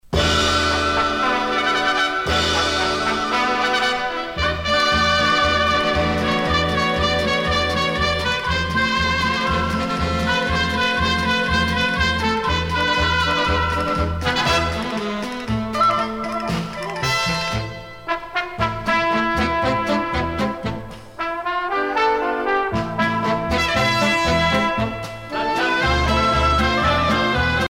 danse : paso-doble
Pièce musicale éditée